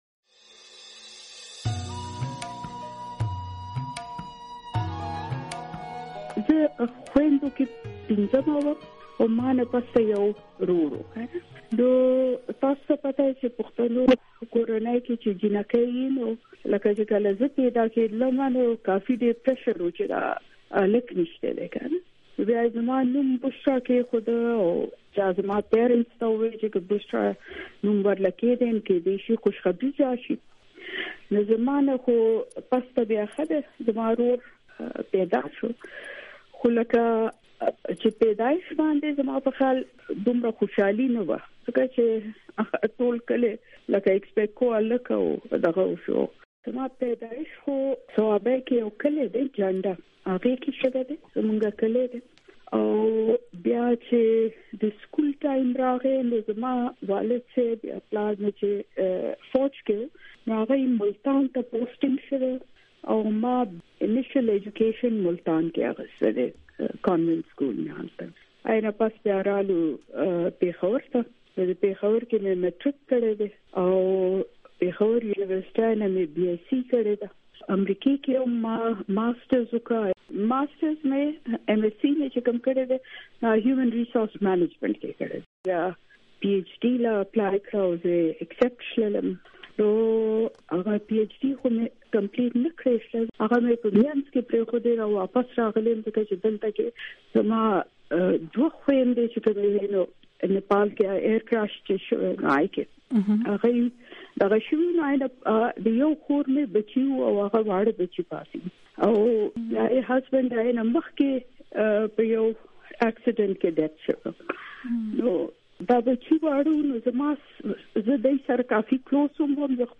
د بشرا ګوهر د ژوند کیسه د هغې نه واورئ